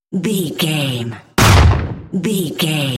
Dramatic stab wood hit
Sound Effects
Atonal
heavy
intense
dark
aggressive
hits